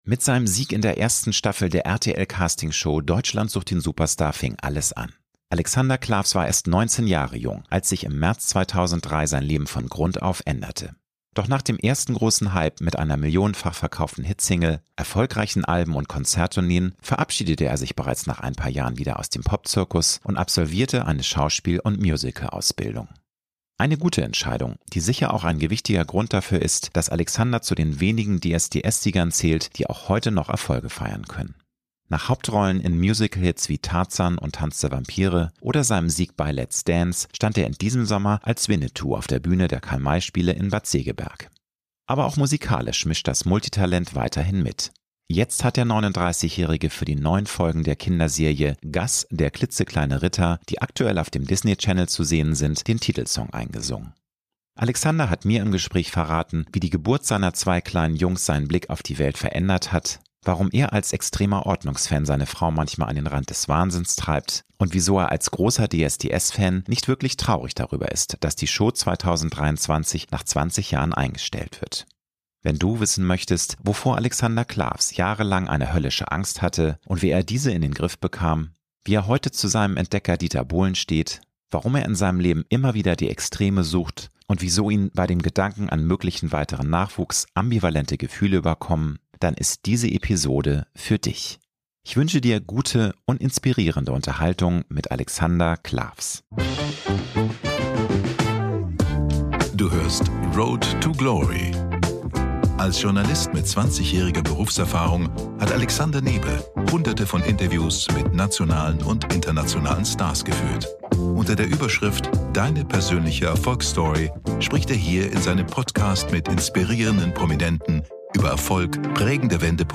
~ Road to Glory - Promi-Talk